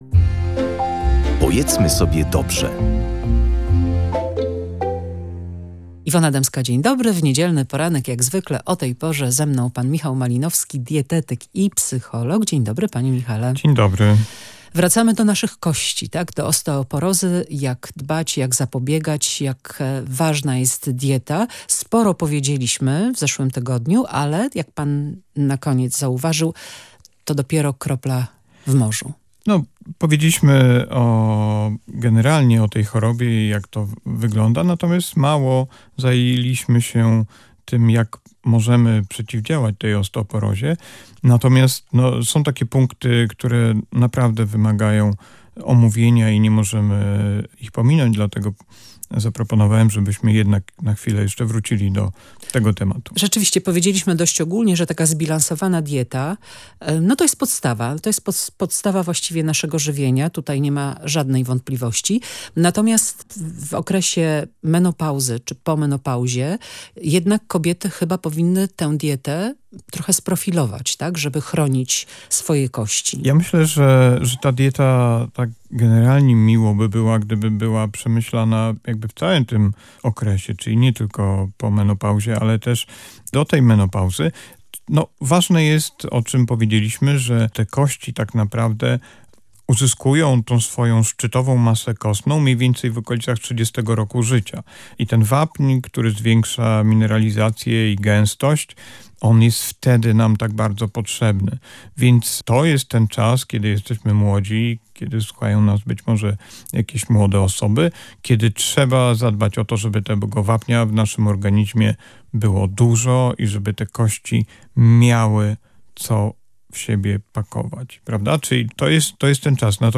W audycji „Pojedzmy sobie dobrze” rozmawialiśmy o wpływie żywienia na układ kostny i jego profilaktyce. Co „lubią” a czego „nie lubią” nasze kości?